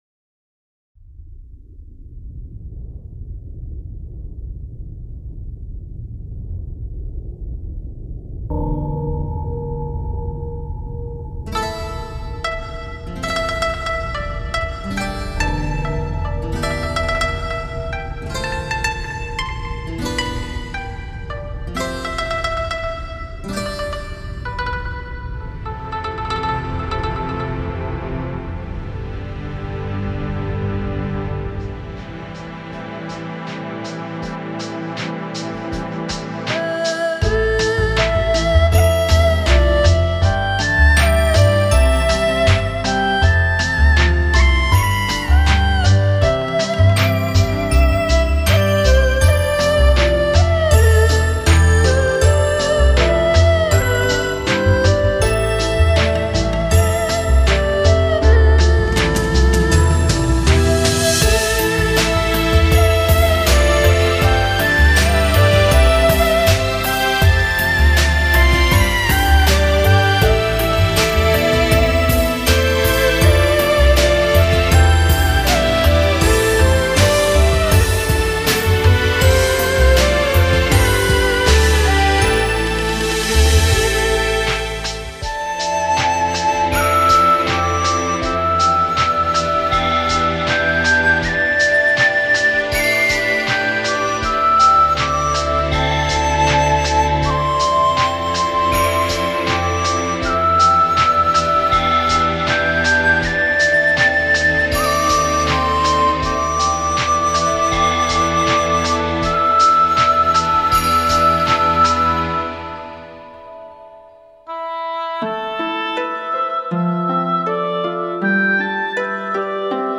网友评价：这是一张有着浓厚东方文化气息的碟，这张同人Arrange CD甚至连介绍都寥寥无几，可谓非常珍贵。
二胡的演奏可谓无可挑剔，加上婉转悠扬的旋律